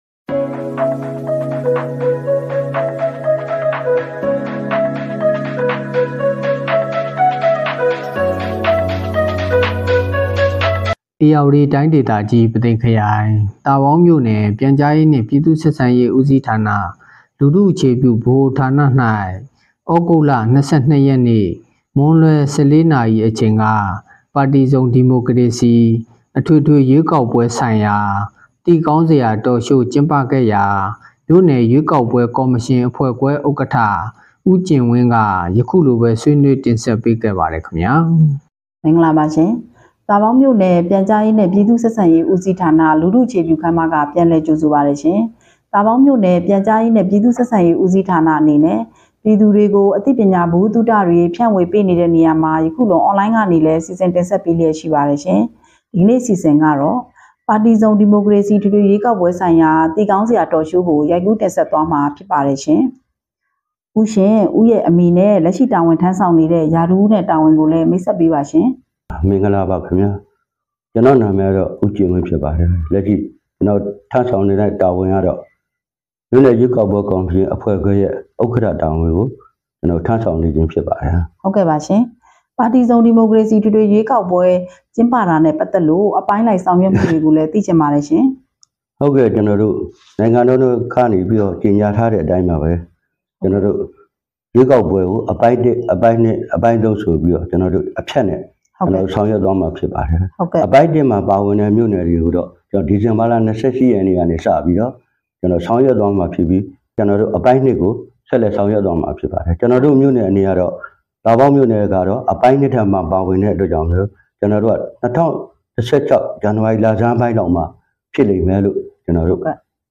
လူထုအခြေ ပြုဗဟိုဌာနတွင် ပါတီစုံ ဒီမိုကရေစီအထွေထွေရွေးကောက်ပွဲဆိုင်ရာသိကောင်းစရာ Talk show ကျင်းပ သာပေါင်း သြဂုတ် ၂၃ ပေးပို့သူ